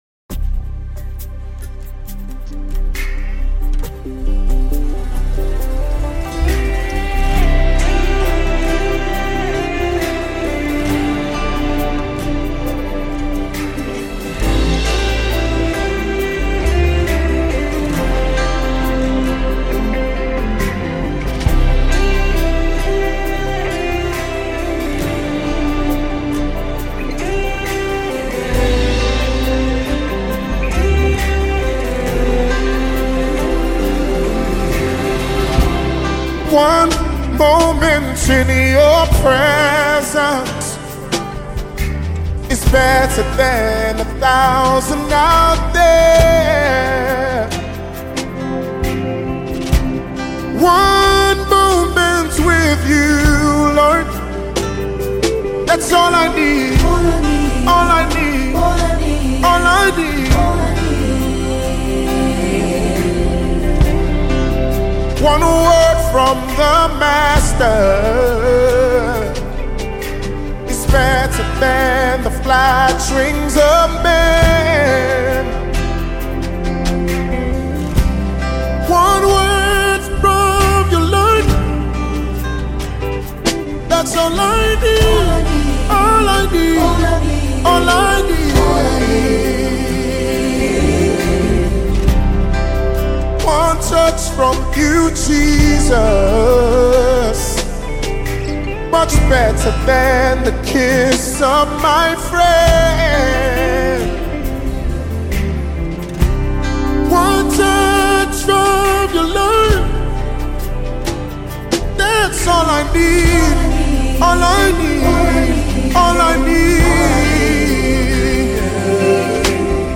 spirit-filled song